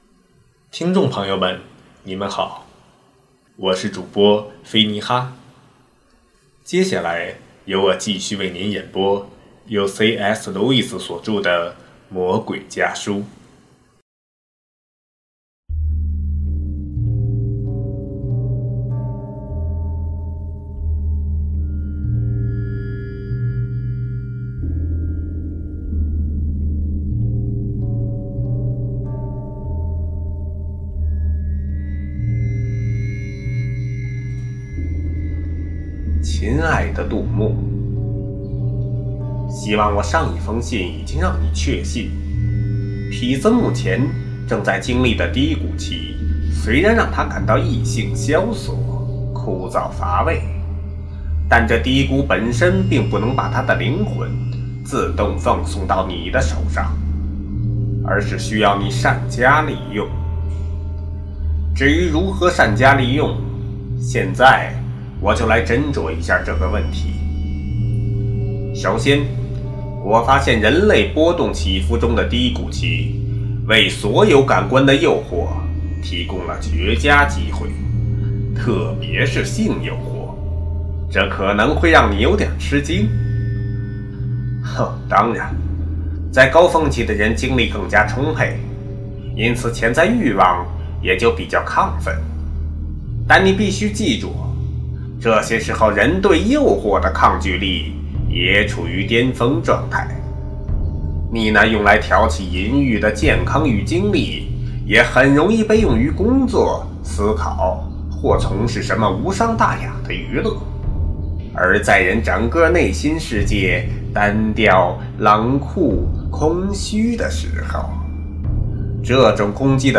首页 > 有声书 | 灵性生活 | 魔鬼家书 > 魔鬼家书：第九封书信